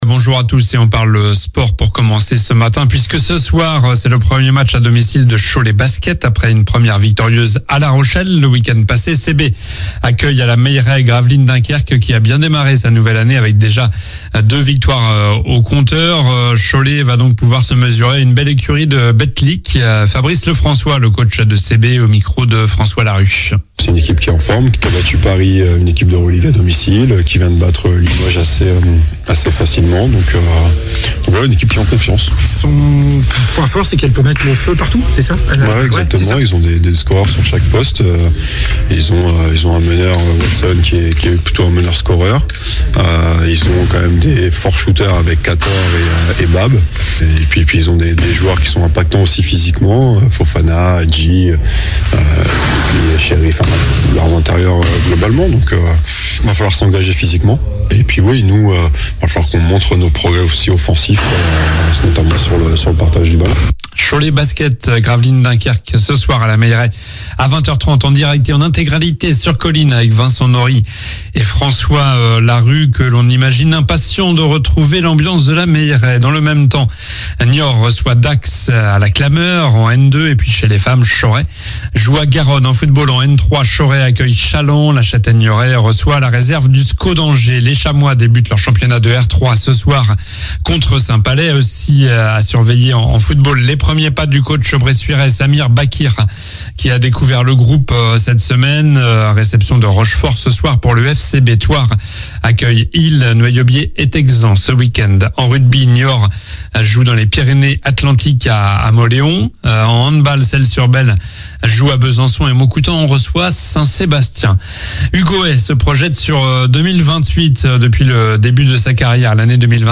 Journal du samedi 05 octobre (matin)